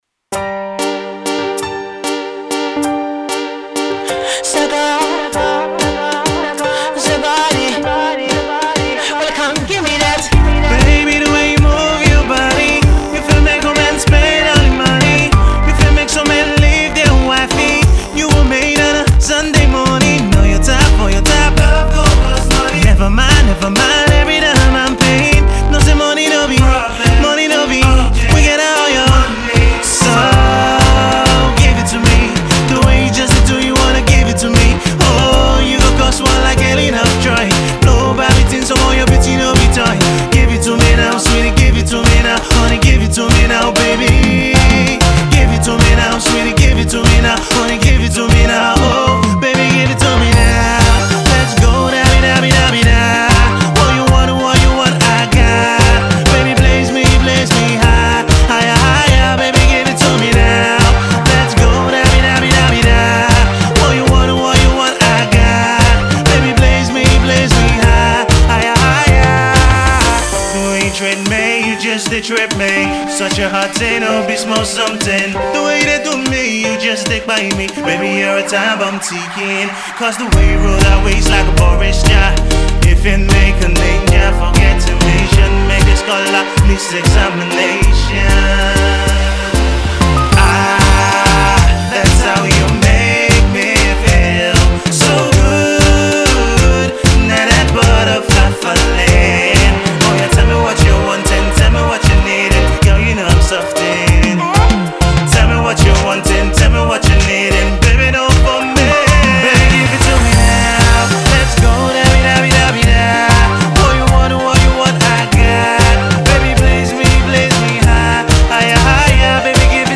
Pop
This is a love themed & catchy number